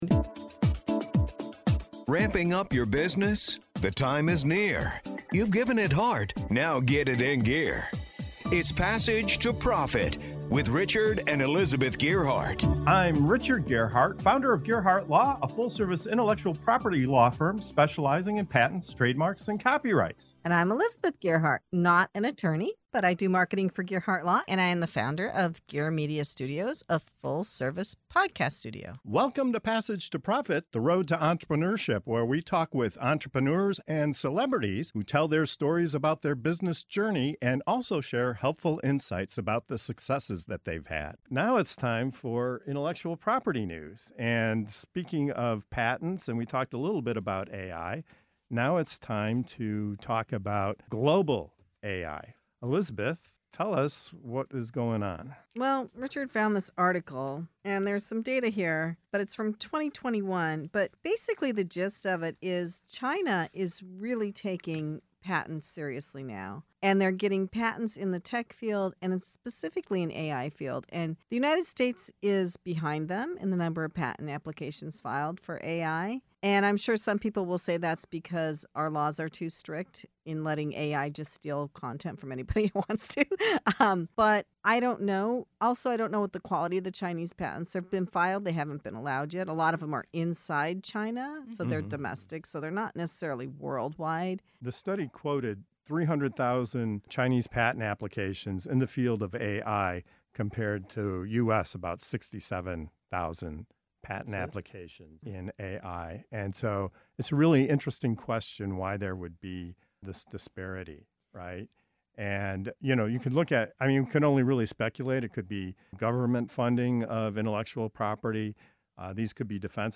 With China filing over 300,000 AI-related patents compared to just 67,000 in the U.S., our hosts unpack what this massive disparity means for innovation, entrepreneurship, and the future of tech. Is it a strategic move, a numbers game, or a warning sign for the U.S.?